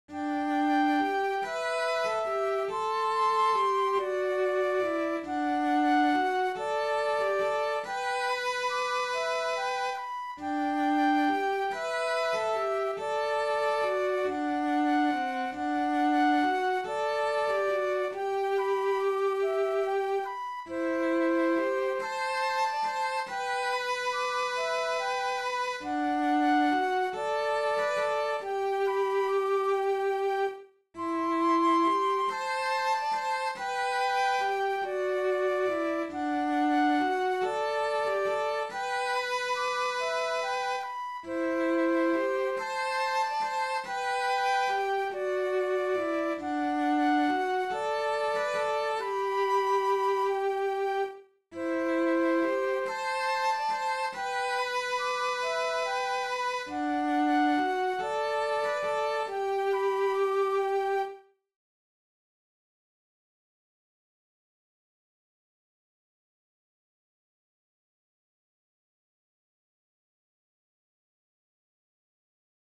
Rantaa-kohti-sello-ja-huilu.mp3